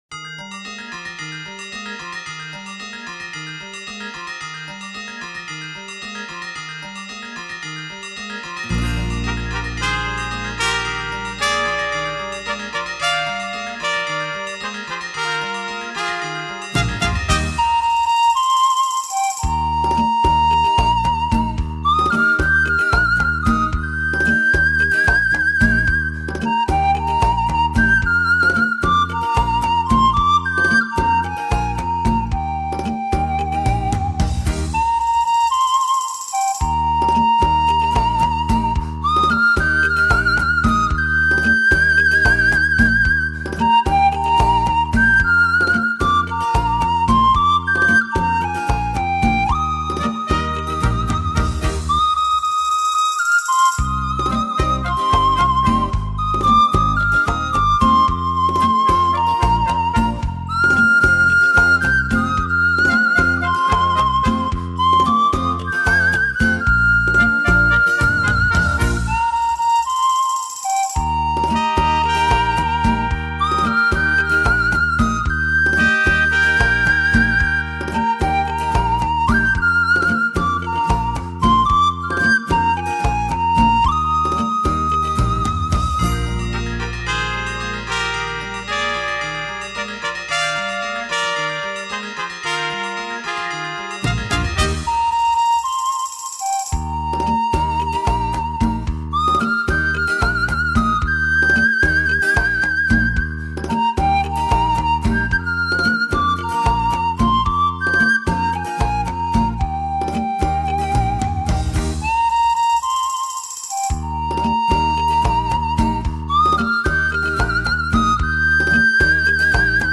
音乐类型: 民乐
用古老的中国乐器奏异国他乡的音乐。